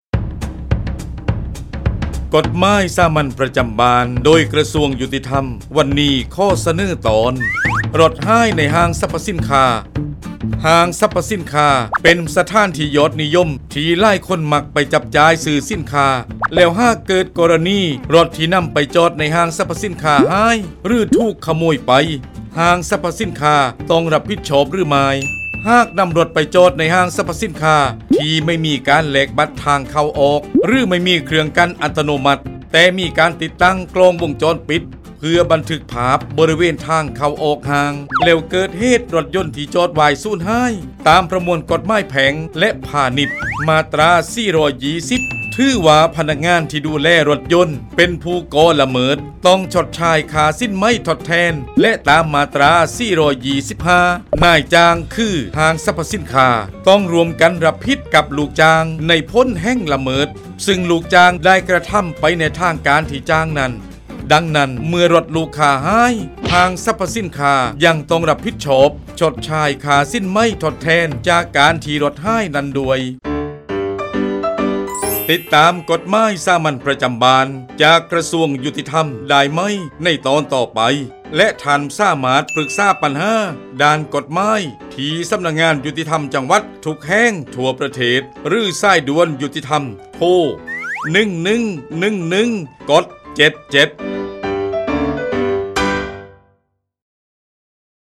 กฎหมายสามัญประจำบ้าน ฉบับภาษาท้องถิ่น ภาคใต้ ตอนรถหายในห้างสรรพสินค้า
ลักษณะของสื่อ :   บรรยาย, คลิปเสียง